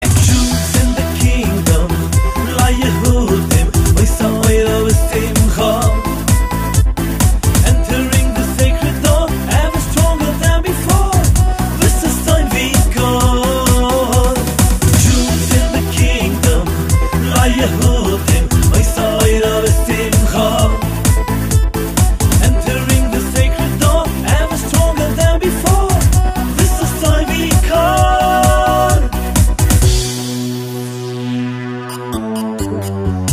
• Качество: 256, Stereo
поп
dance
Electronic
Jewish Dance
Еврейская танцевальная музыка